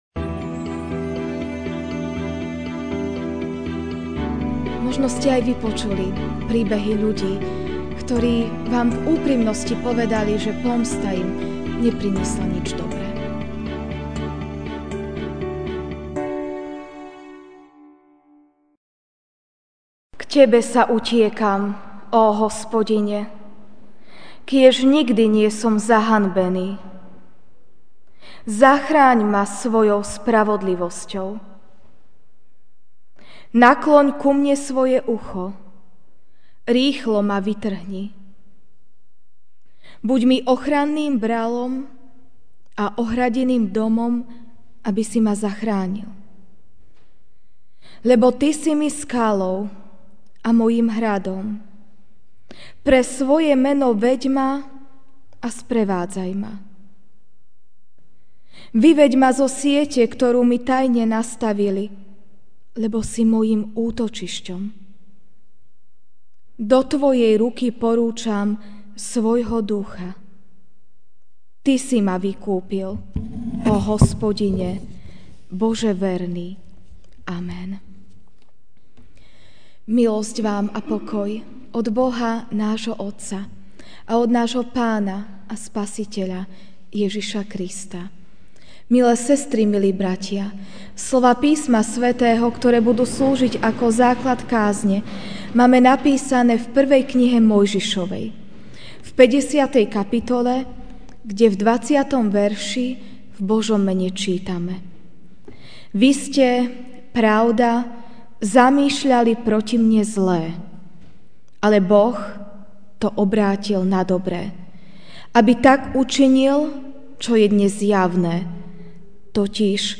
MP3 SUBSCRIBE on iTunes(Podcast) Notes Sermons in this Series Ranná kázeň: Dobrým premáhaj zlé!